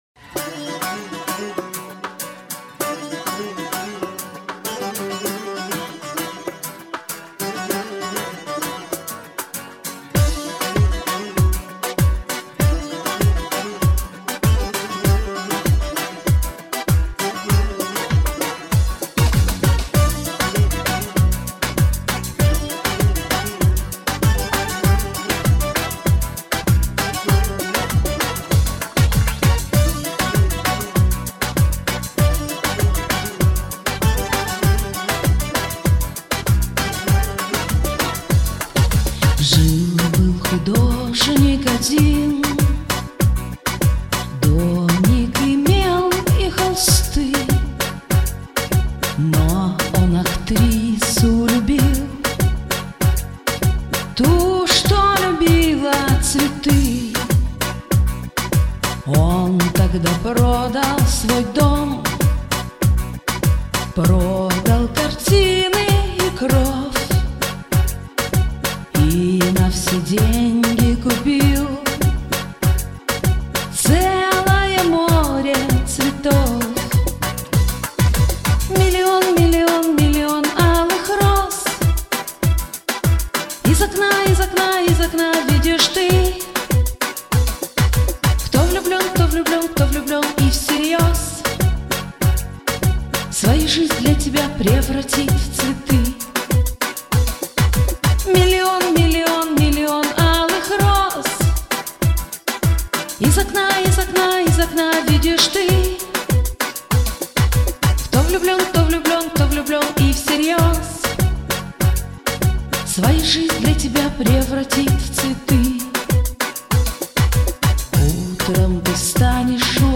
Но предпочтение все таки хрустальному голосу.555